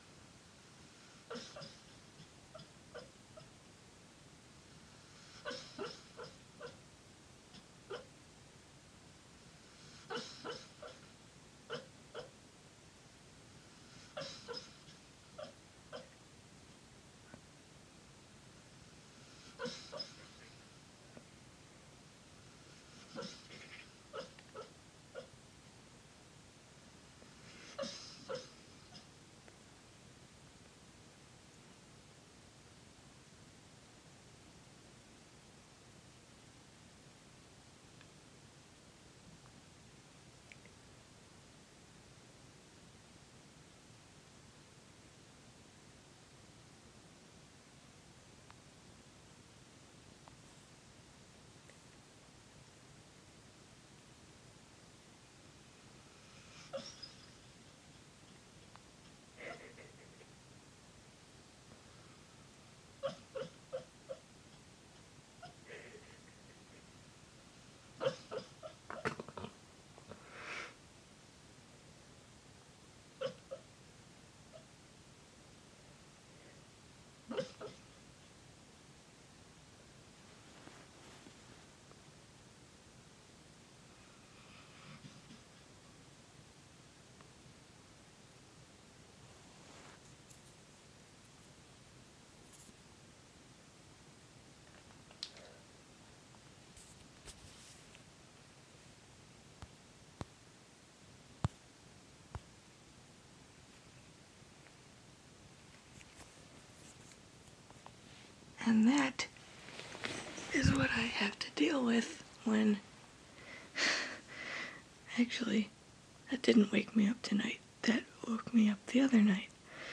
puppy dreaming